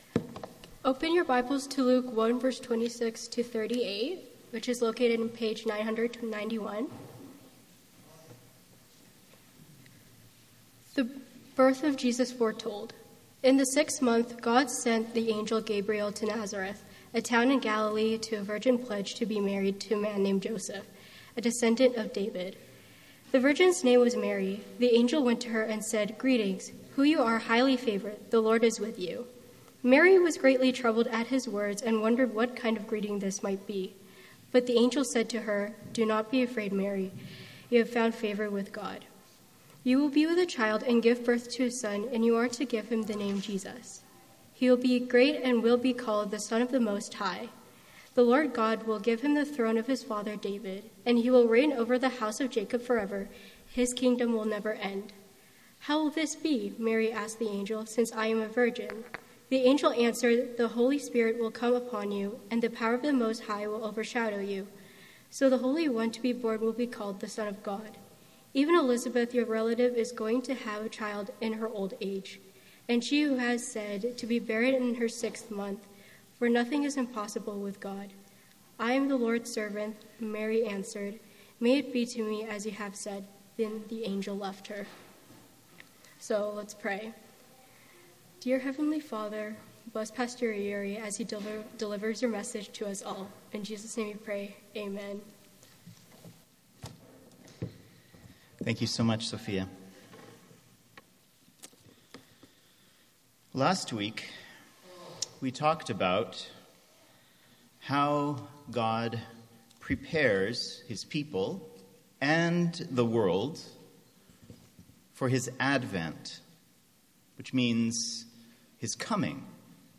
with a reading of Holy Scripture and prayer
MP3 File Size: 24.4 MB Listen to Sermon: Download/Play Sermon MP3